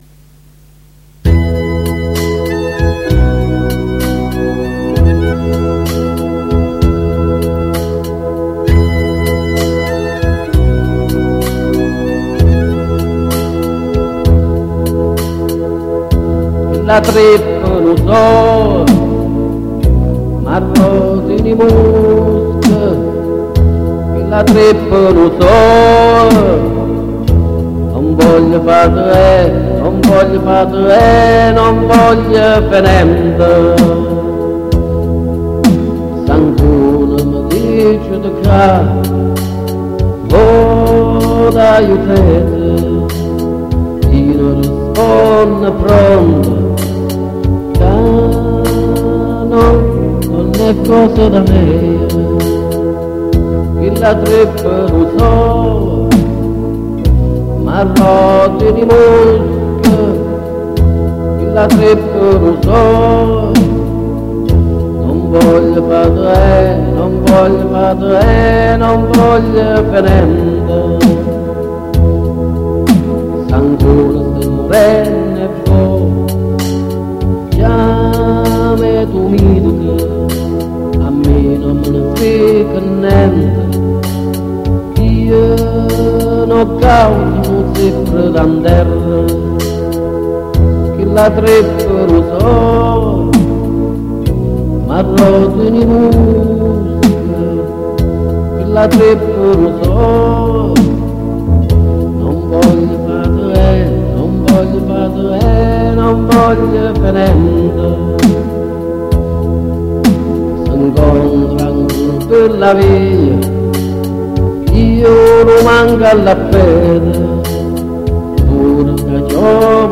in dialetto cannese